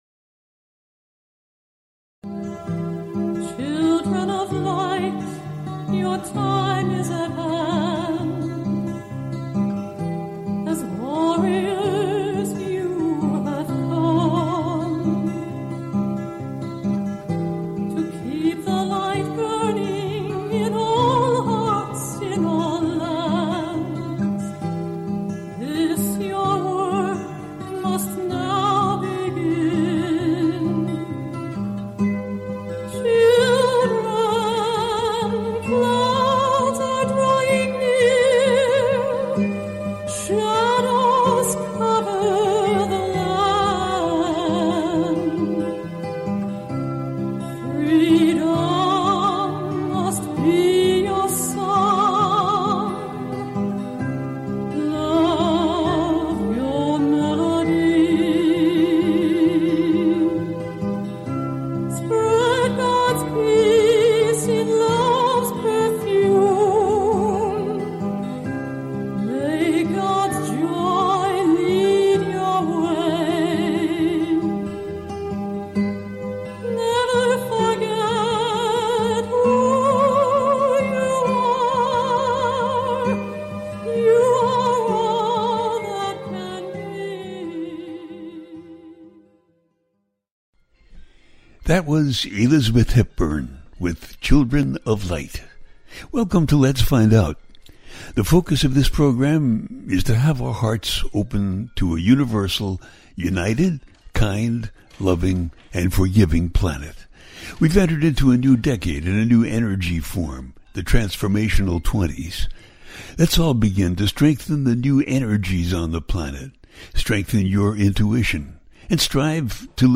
Talk Show Episode, Audio Podcast, Lets Find Out and A Teaching Show, August-the month of destiny as Venus retrogrades in Leo-where is the Love? on , show guests , about a teaching show,August the month of destiny,Venus retrogrades in Leo,where is the Love, categorized as Entertainment,News,Paranormal,Science,Self Help,Society and Culture,Spiritual,Astrology,Psychic & Intuitive
The listener can call in to ask a question on the air.
Each show ends with a guided meditation.